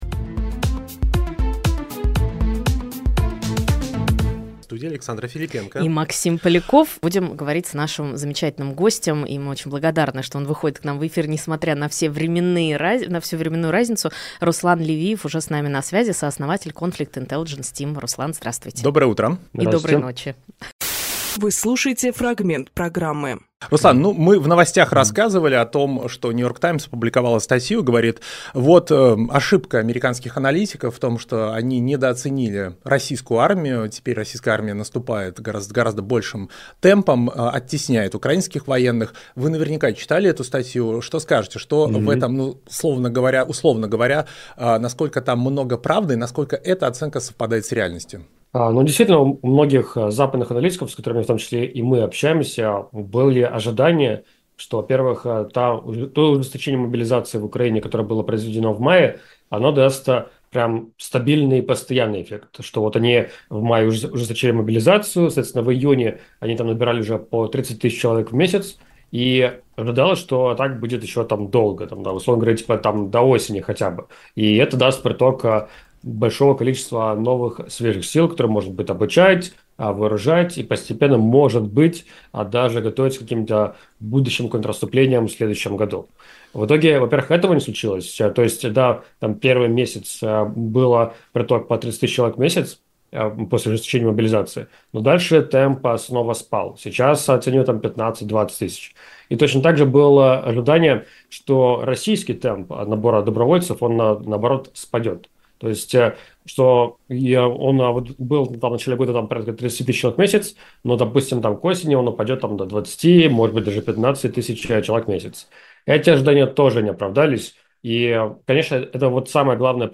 Фрагмент эфира от 03.11.24